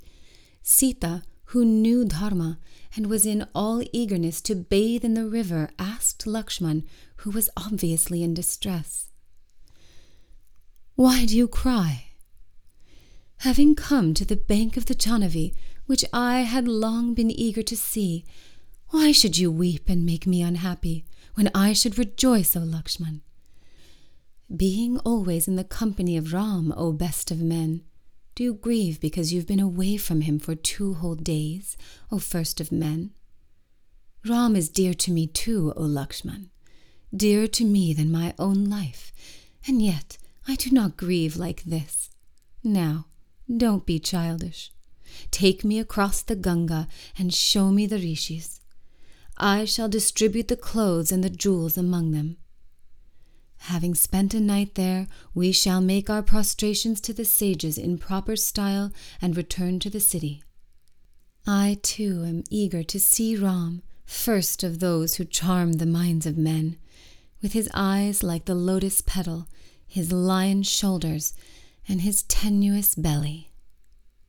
part audiobook, part guided course
Commentary
Narration